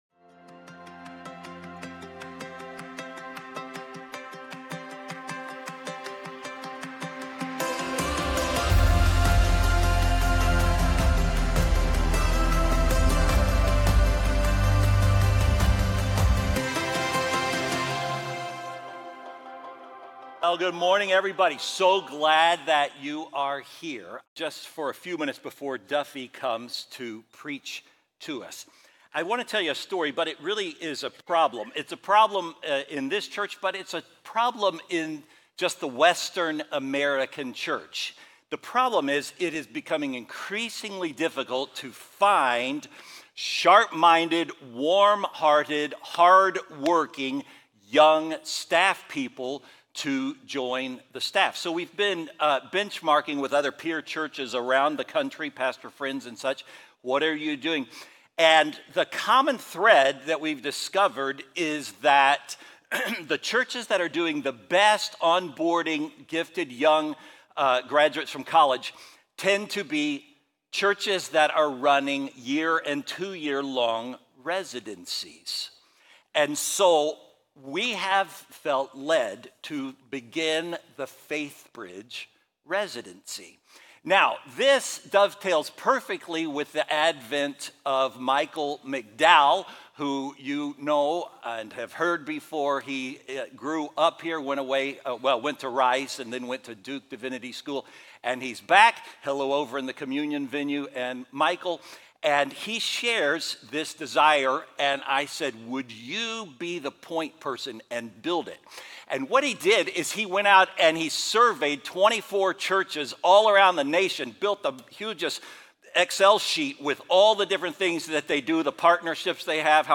Faithbridge Sermons